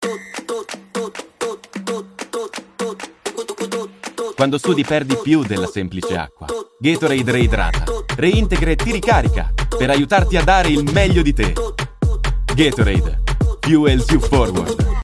Male
Confident, Engaging, Friendly, Natural, Versatile, Corporate, Deep, Young
TV COMMERCIAL 15 - SPARCO TEAMWORK IMPUL....mp3
Microphone: SE Electronics 2200A / Shure MV7